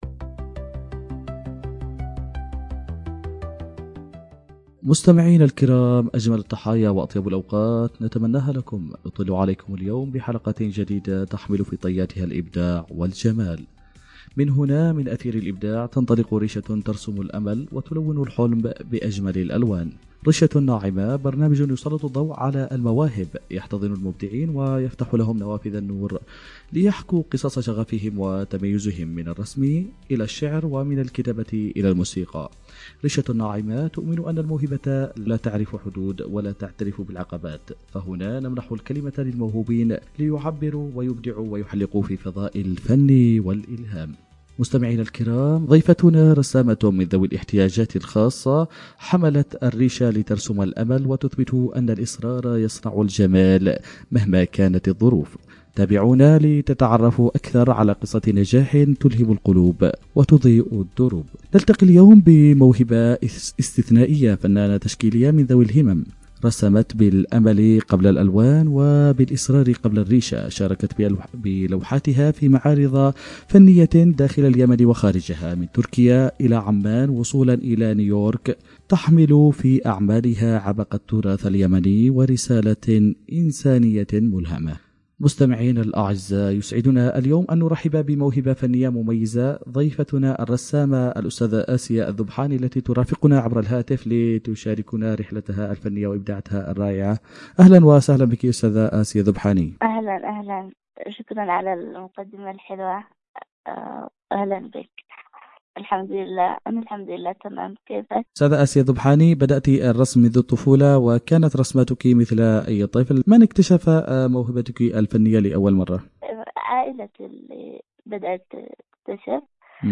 في حوار ملهم حول قوة الفن، وكيف يصبح وسيلة للتعبير، المقاومة، وبناء الأمل رغم التحديات.